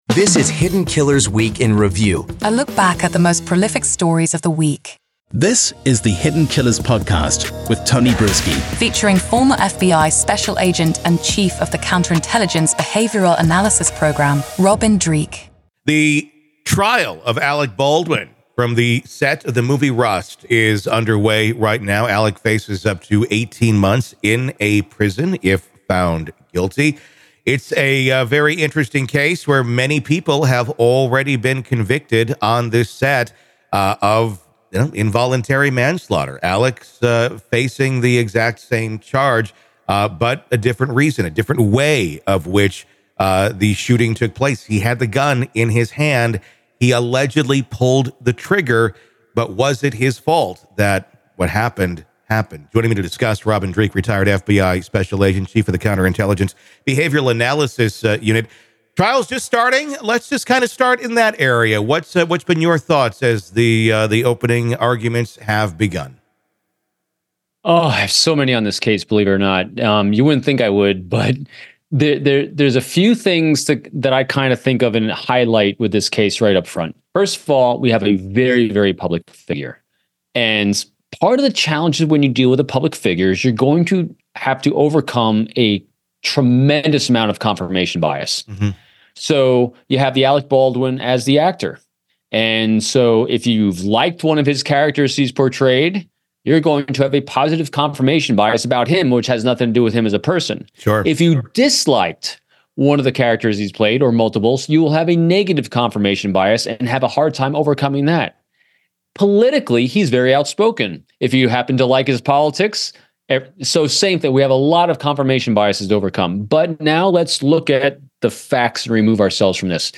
Showbiz News Talk News True Crime Rust Movie Shooting Trial Of Alec Baldwin True Crime Today
Each episode navigates through multiple stories, illuminating their details with factual reporting, expert commentary, and engaging conversation.